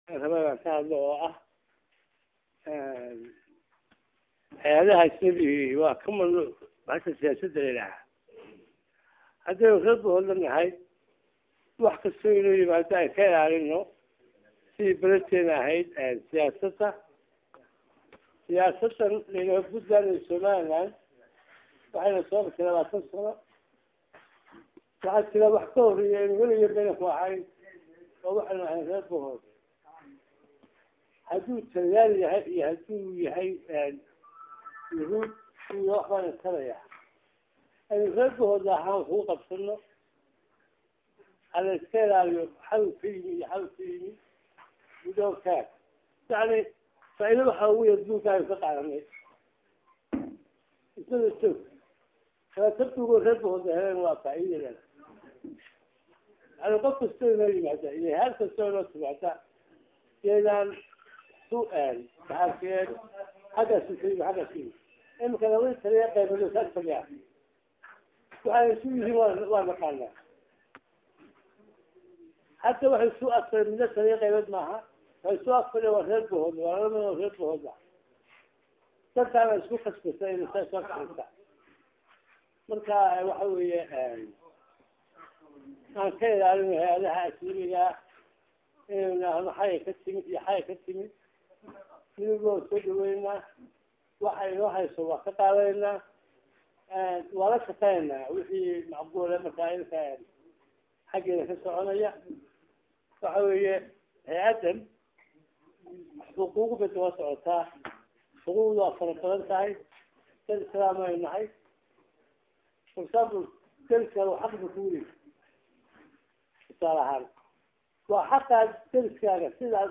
Dhagayso Hadaladii gudoomiyaha ahna duqa magaalada Buuhoodle Xuseen Dacwi
Waxaa Maanta Magaalada Buuhoodle lagu Qaybtay kulan balaadhan oo looga hadlay xaquuqda aadanaha iyo taciyada loo gaysto dadka ka soo Jeeda koonfurta somalia iyo Waliba dadka la adoonsado Waxaana kulan kaasi soo Qaban Qaabiyey NGO UNGAM oo Saldhigeesu yahay magaalada Boosaaso ee Xarunta gobolka bari.